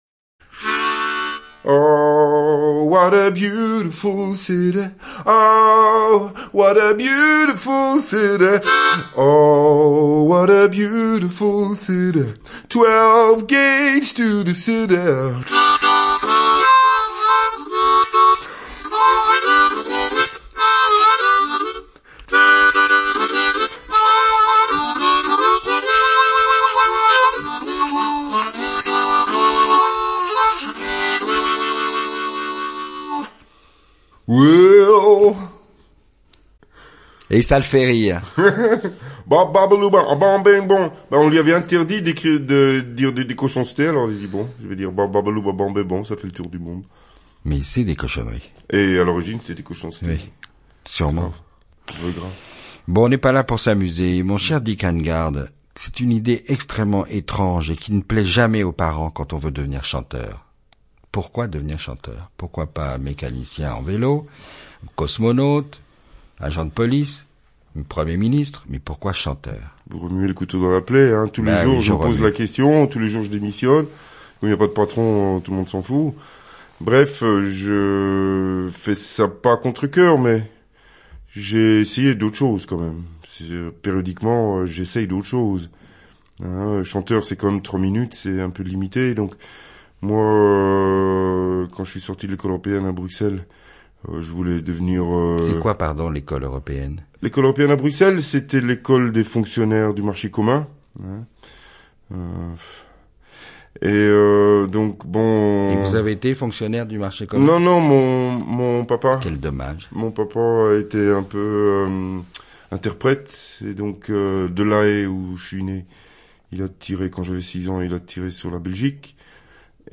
C'est un peu surprenant de n'avoir mis aucune chanson sur ce CD, juste un entretien d'une grosse demi-heure. Mais cet entretien est passionnant et permet de mieux connaître le parcours et les points de vue de Dick Annegarn , sur pourquoi il est devenu chanteur, Bruxelles, les Berbères , son attitude par rapport au monde de la variété, les américains...
Et puis, il y a quand même de la musique, car il a sa guitare et un harmonica avec lui et il fait partie de ces musiciens (on ne va pas s'en plaindre) qui s'expriment spontanément et irrésistiblement en musique.